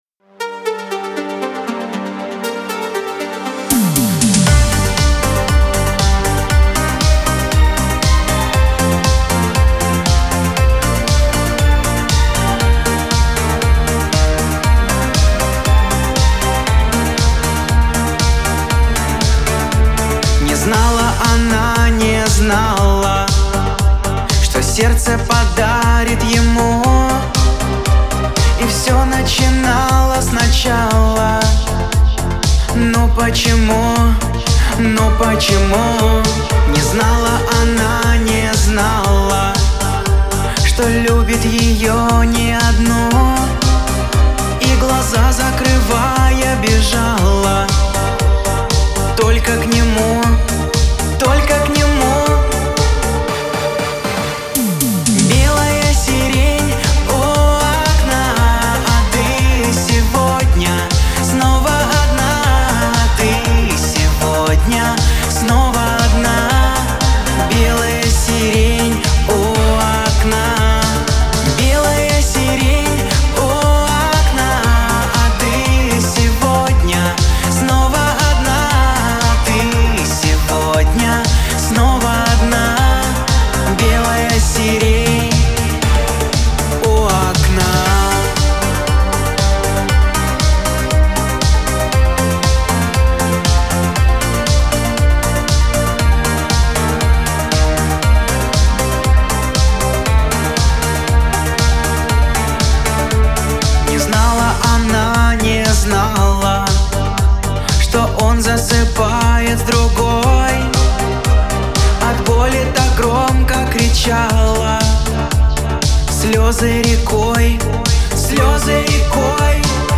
Русский шансон